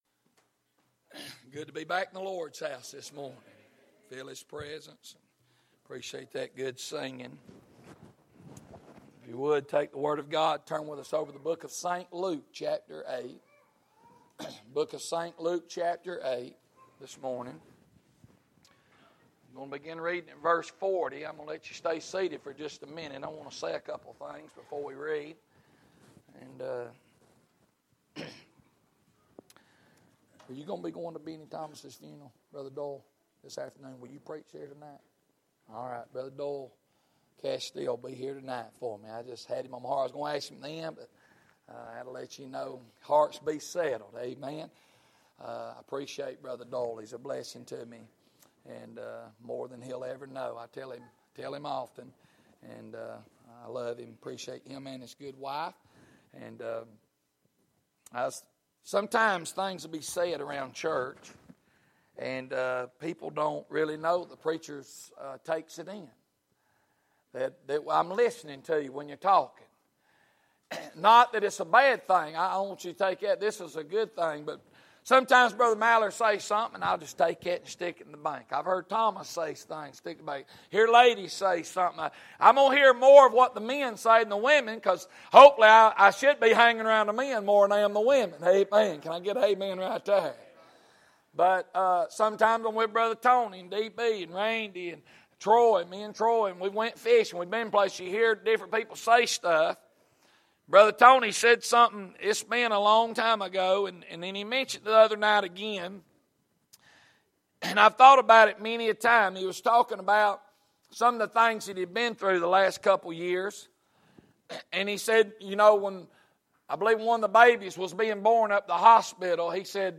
Sermon media